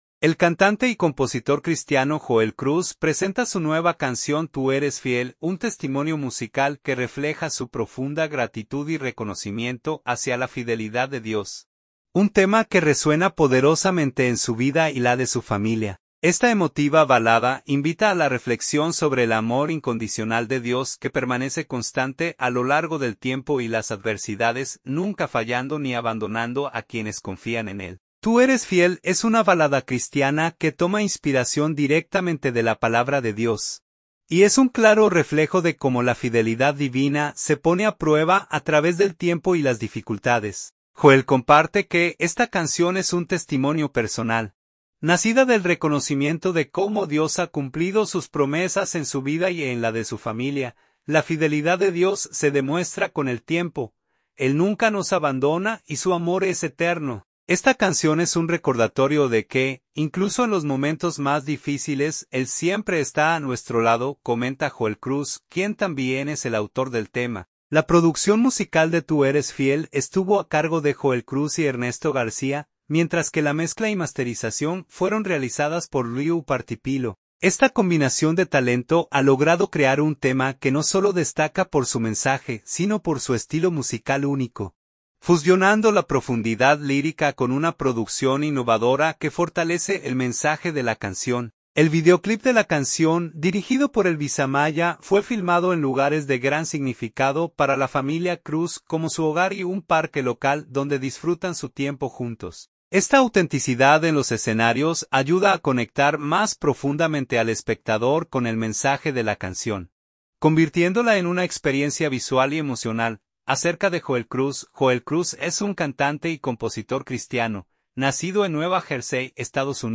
es una balada cristiana